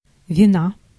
Ääntäminen
IPA: /vʲɪˈna/